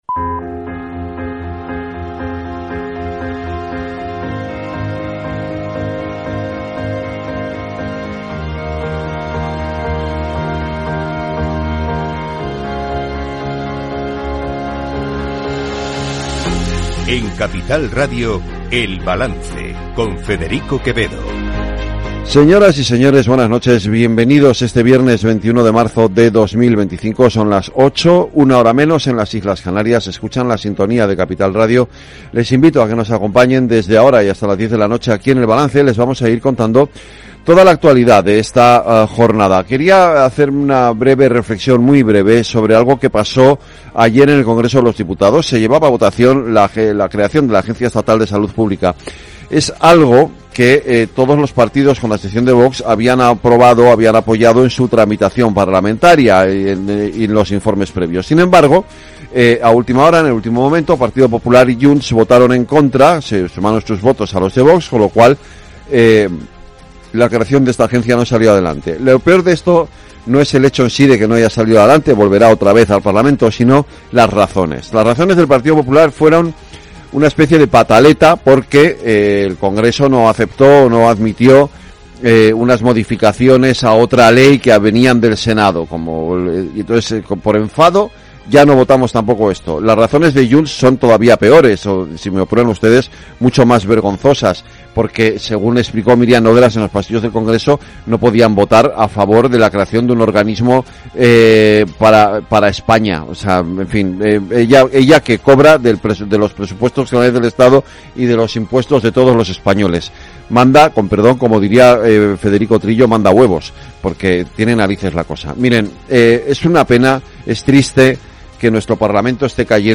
El Balance es el programa informativo nocturno de Capital Radio, una manera distinta, sosegada y reflexiva de analizar la actualidad política y económica